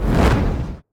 fire2.ogg